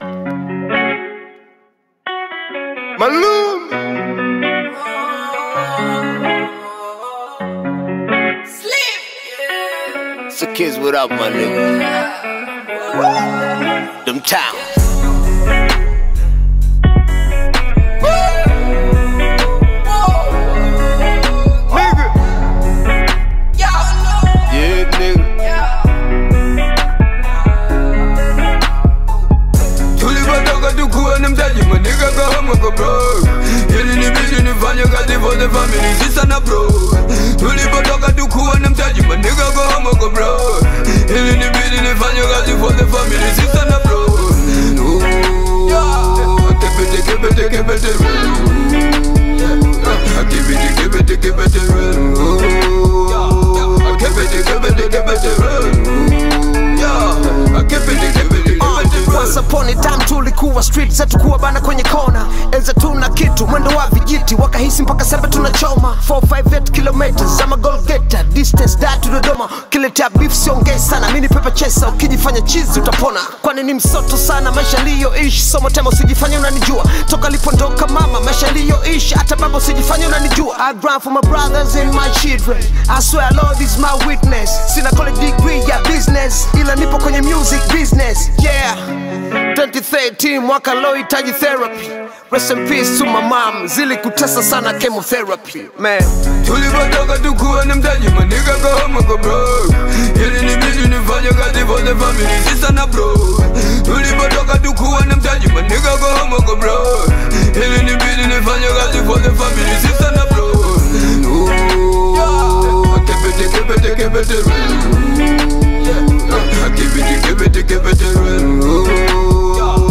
Tanzanian music
smooth delivery, and quality production